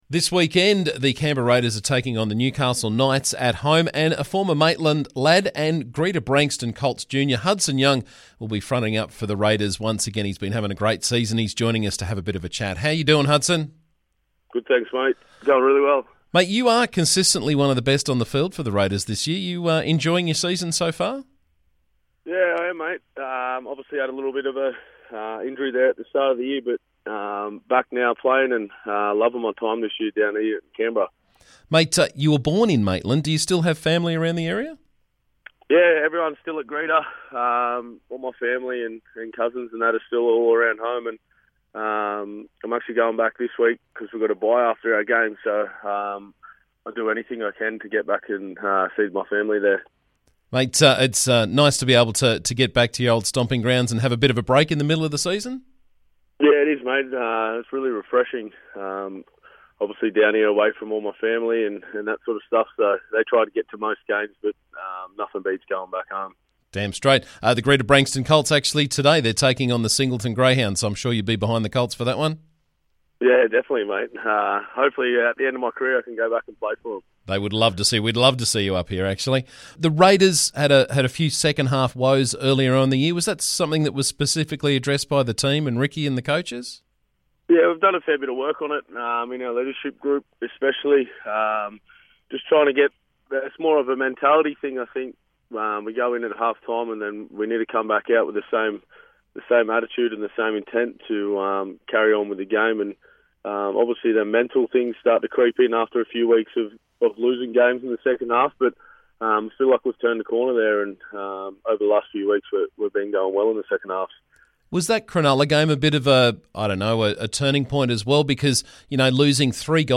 Hudson Young has been having a great season with the Canberra Raiders. Tomorrow they take on the Newcastle Knights so we had a chat with the former Great Branxton Colts junior.